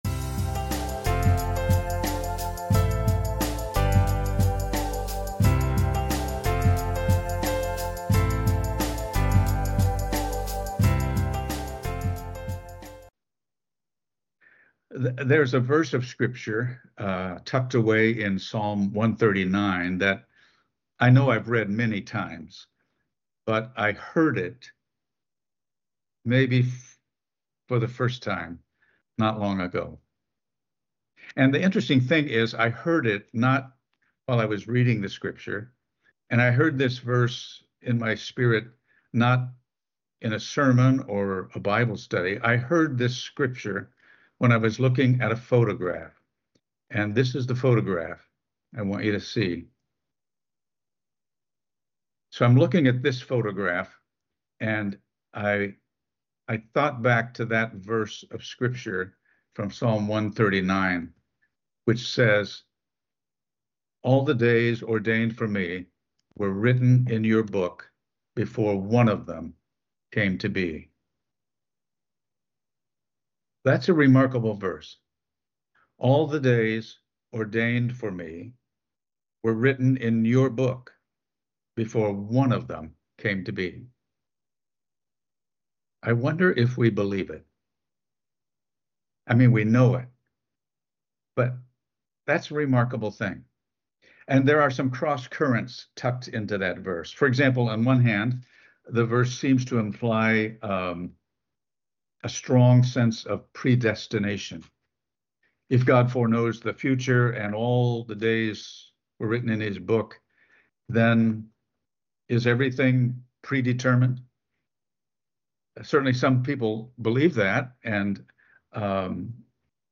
NBC Audio Chapel Services